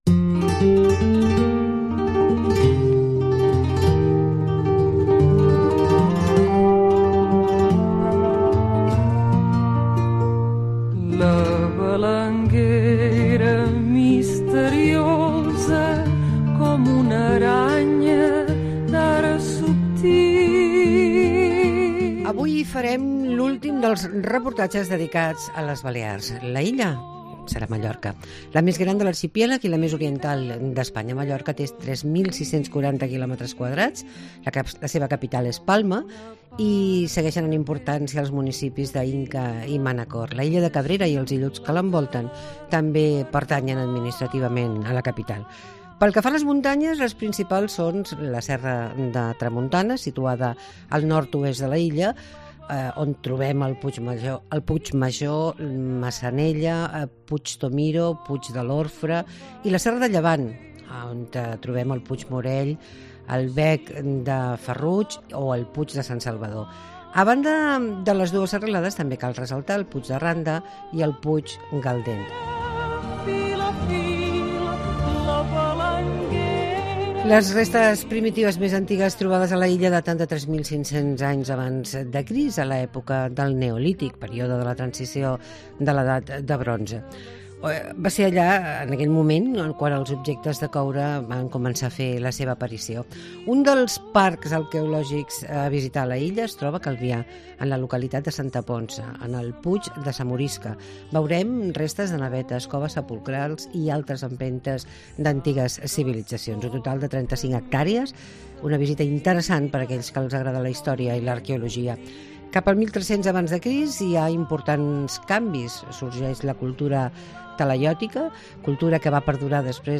Visitem Mallorca i coneixem Palma . La regidora de Turisme, Joana Maria Adrover ens parla de la ciutat